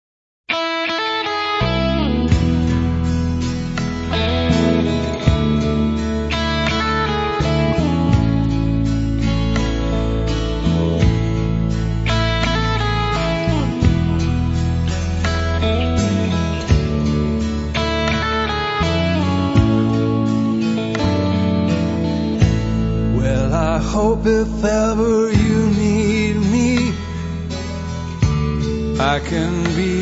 chitarra, mandolino
pianoforte
tromba
violino
• country music
• registrazione sonora di musica